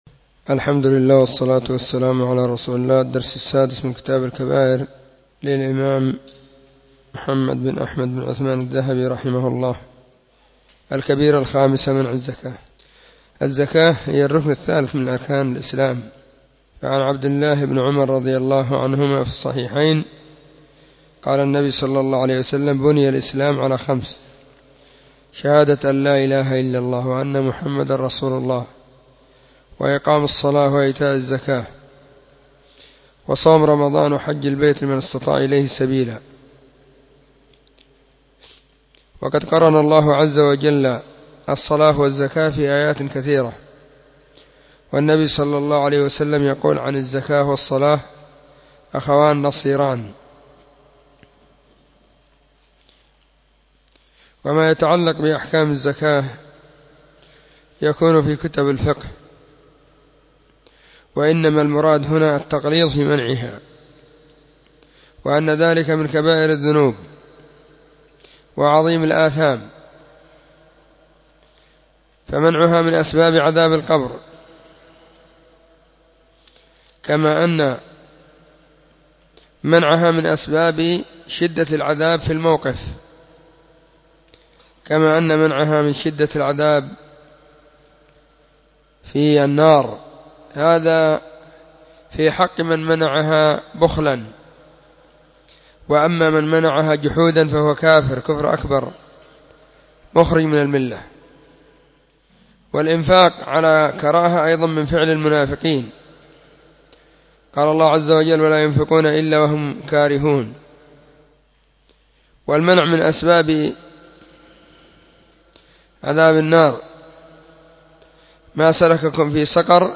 📖كتاب الكبائر 📀 الدرس :-6
🕐 [بين مغرب وعشاء – الدرس الثاني]
كتاب-الكبائر-الدرس-6.mp3